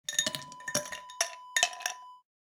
Ice Cube Drop Wav Sound Effect #3
Description: The sound of ice cubes dropping into a glass
Properties: 48.000 kHz 24-bit Stereo
A beep sound is embedded in the audio preview file but it is not present in the high resolution downloadable wav file.
Keywords: ice, ice cube, cubes, icecube, drop, dropping, drink, cocktail, glass
ice-cube-drop-preview-3.mp3